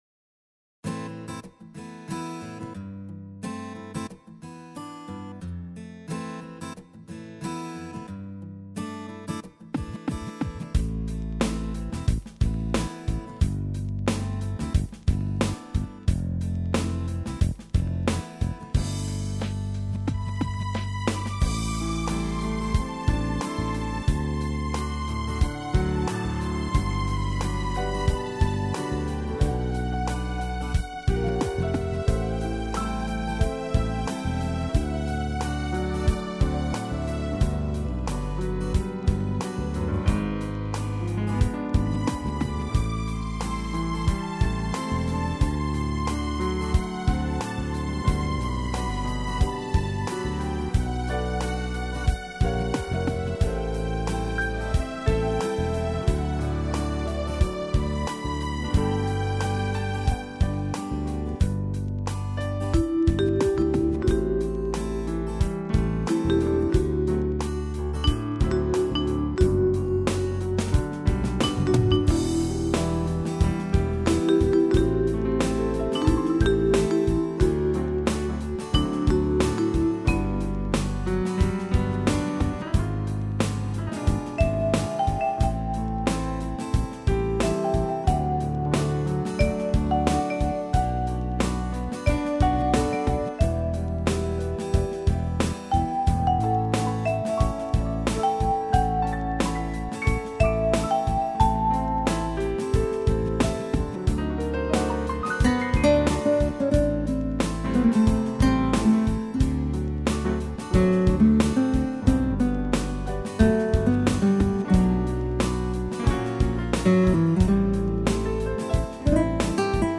CD Instrumental